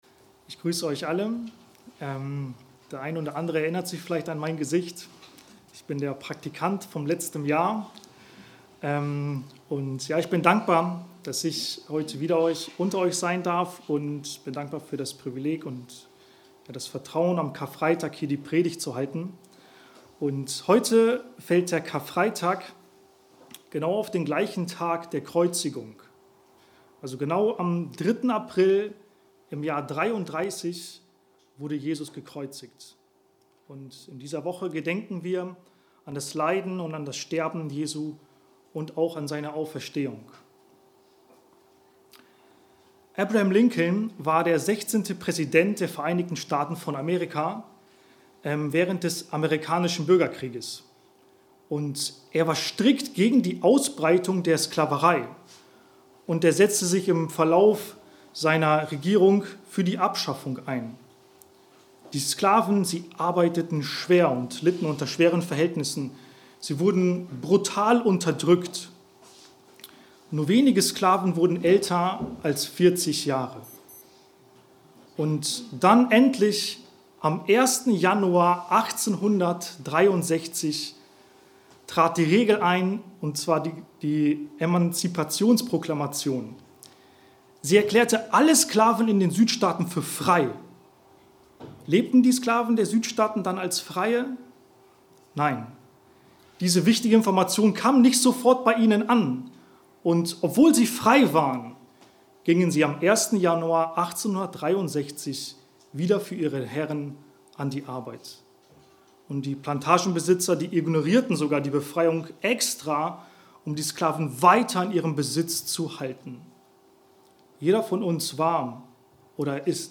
Die Verherrlichung - Der Weg - Der Ursprung Predigt
BERG Osnabrück Gottesdienst